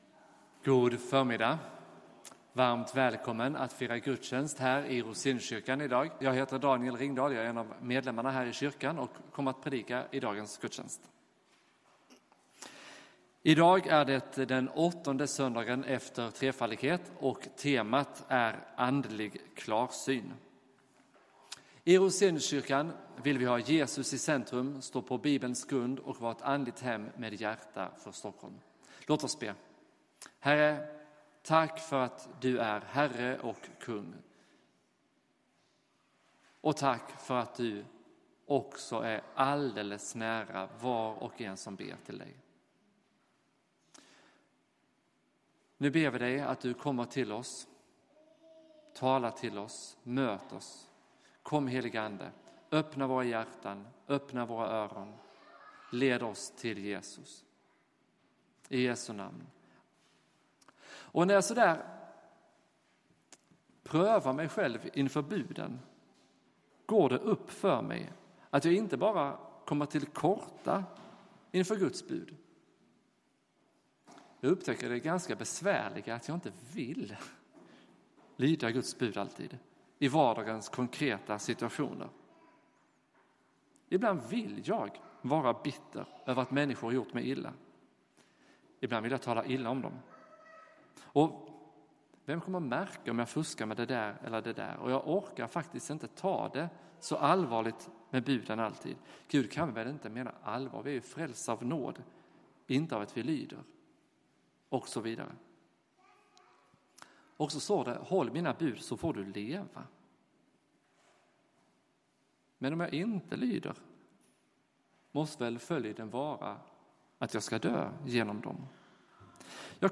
Gudstjänst